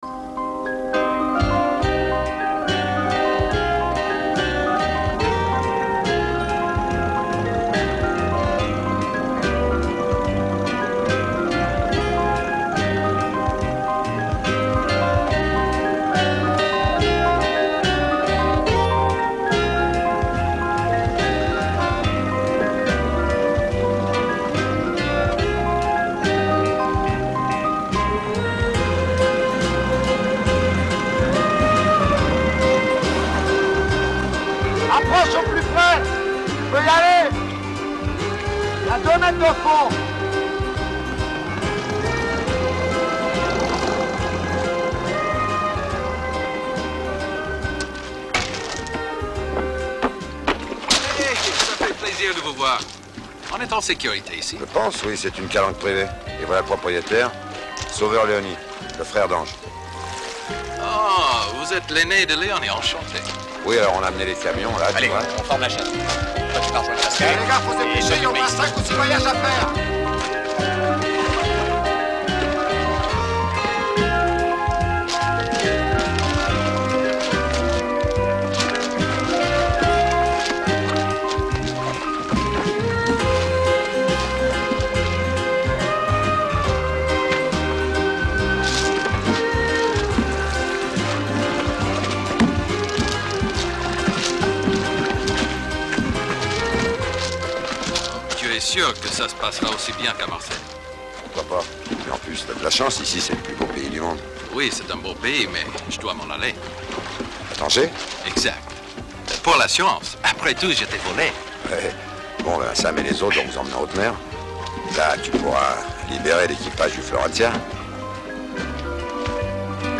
Там мелодия, это лейтмотив по всему фильму. Без слов.
Снял кусочек с фонограммы фильма.